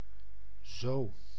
Ääntäminen
IPA: [zo]